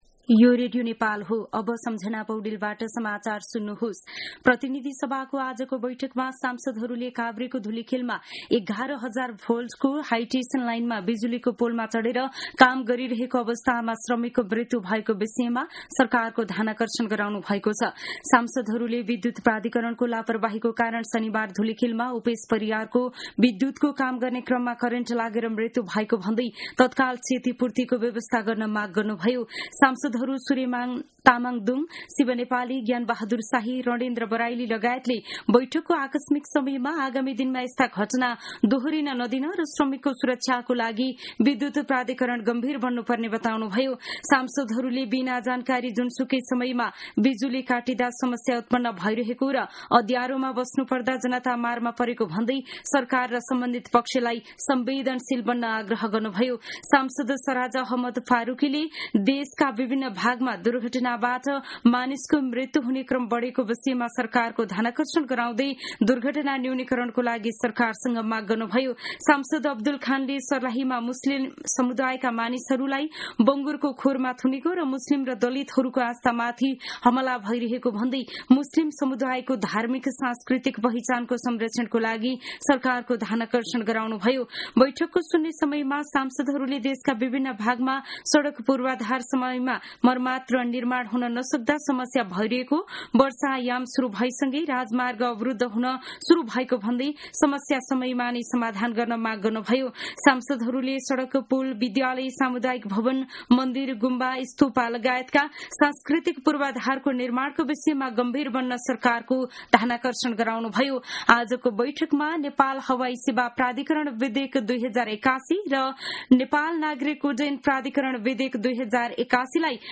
मध्यान्ह १२ बजेको नेपाली समाचार : ४ जेठ , २०८२